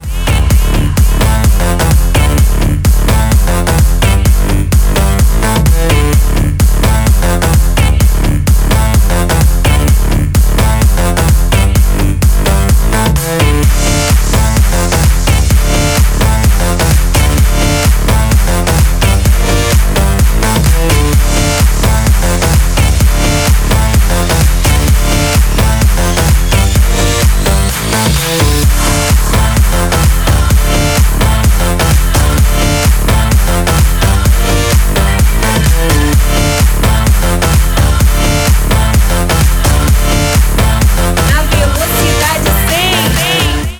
громкие
remix
зажигательные
энергичные
electro house